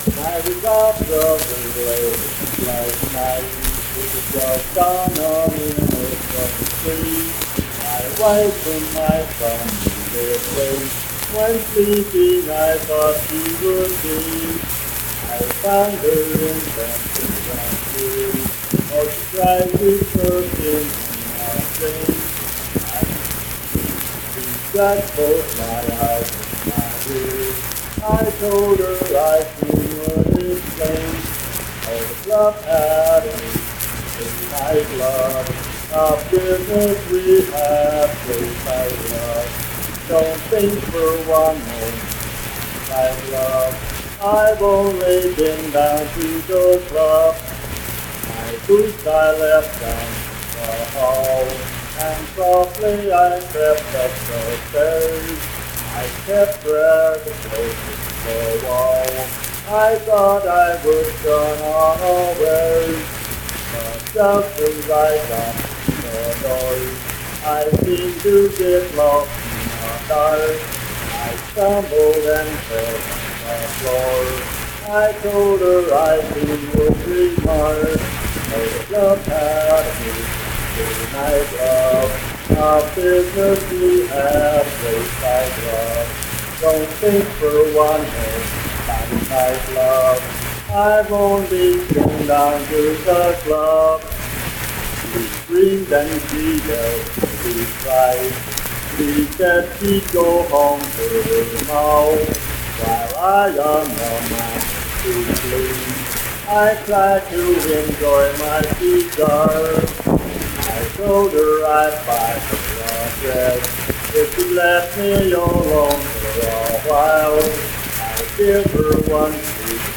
Unaccompanied vocal music performance
Verse-refrain 6(4) & R(4).
Voice (sung)